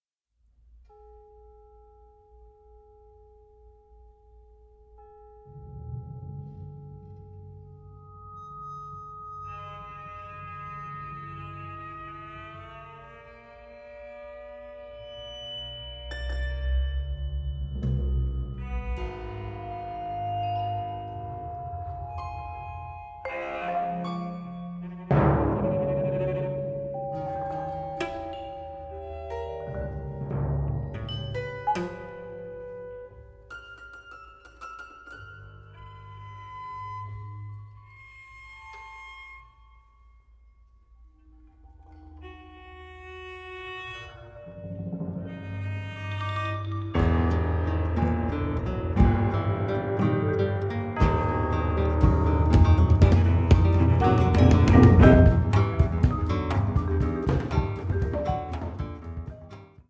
acoustic guitar
piano
drum set
cello
contrabass